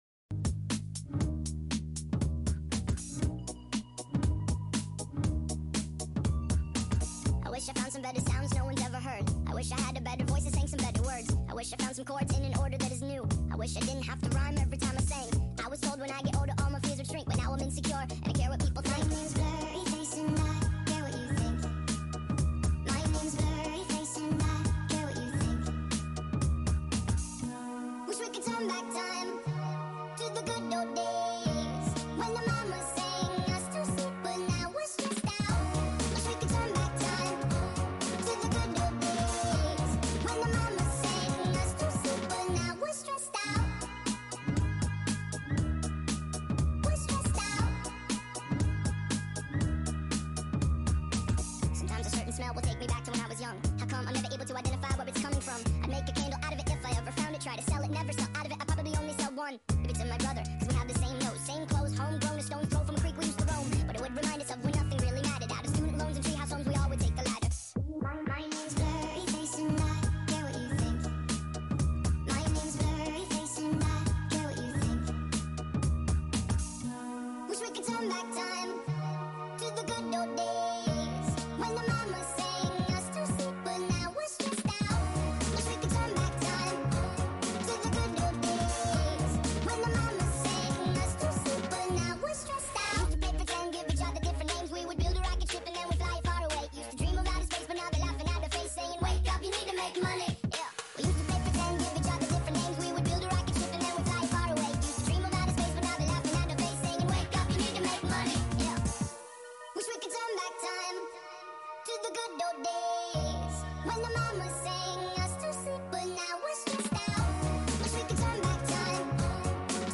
Sped up - full ver.